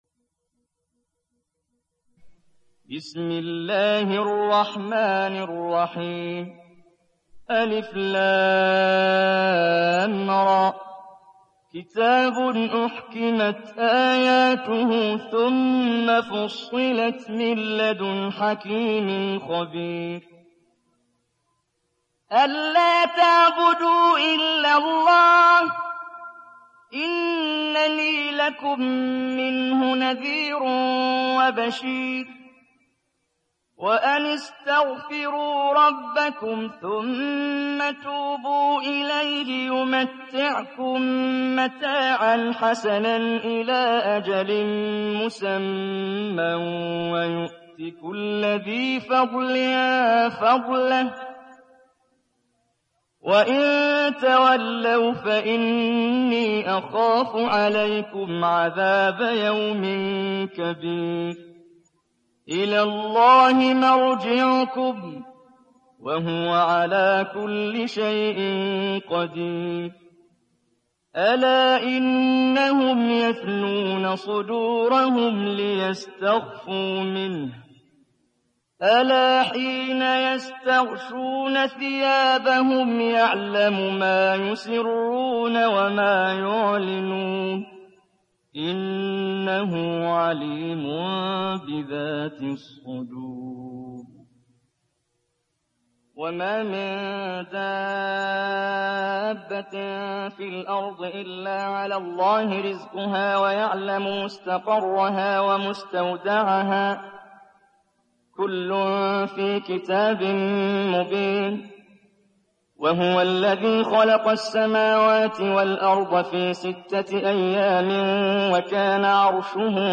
Hud Suresi mp3 İndir Muhammad Jibreel (Riwayat Hafs)
Hud Suresi İndir mp3 Muhammad Jibreel Riwayat Hafs an Asim, Kurani indirin ve mp3 tam doğrudan bağlantılar dinle